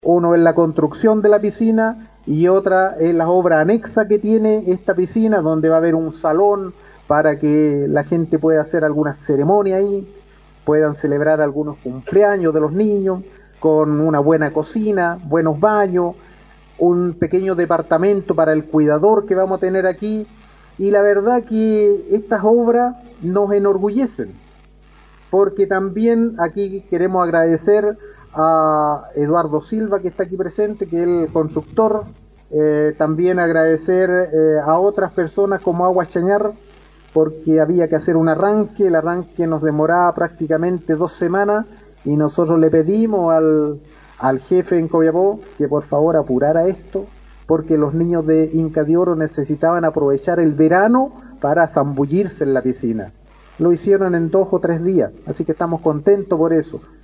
En la localidad de Inca de Oro se realizó un significativo acto de inauguración de una piscina municipal.
Con la idea de poder mejorar la calidad de vida de los habitantes de esta localidad, el alcalde de Diego de Almagro, Isais Zavala destacó en su discurso la importancia de poder trabajar por aquellos proyectos que fueron en un momento “un sueño” y que ahora ya son una realidad, como el Centro de Diálisis Municipal, el Observatorio Astronómico o el Jardín de Abuelitos.